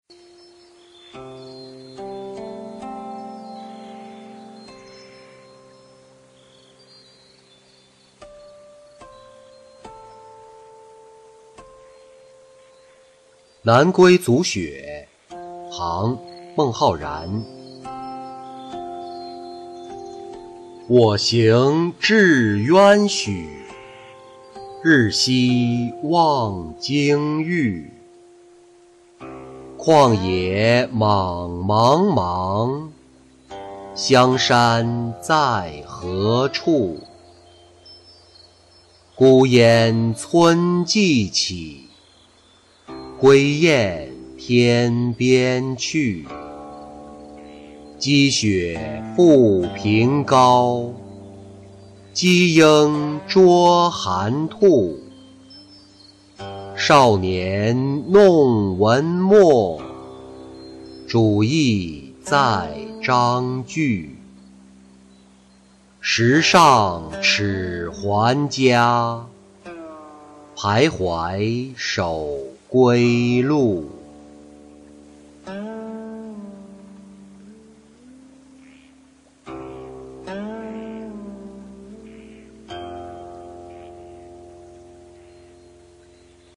南归阻雪-音频朗读